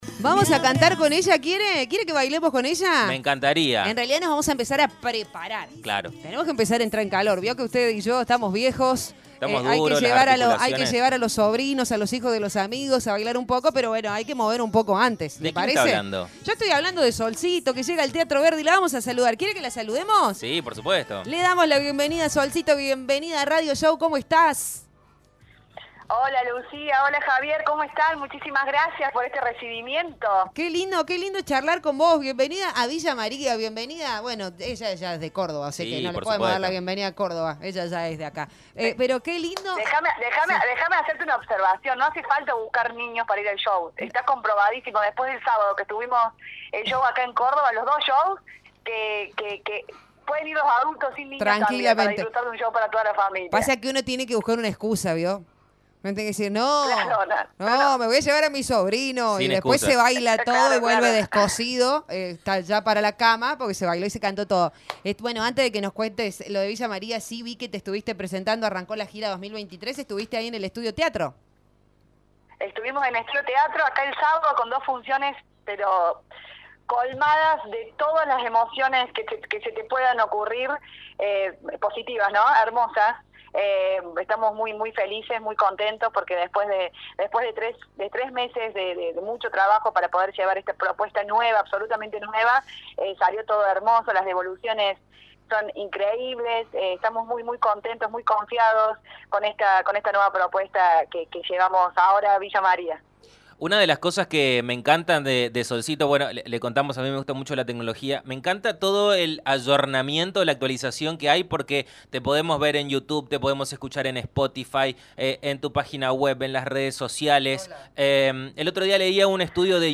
En una entrevista exclusiva con nuestro medio conversó sobre las redes sociales, su estilo para componer y nos adelantó un poquito de lo que se verá en su show en nuestra ciudad.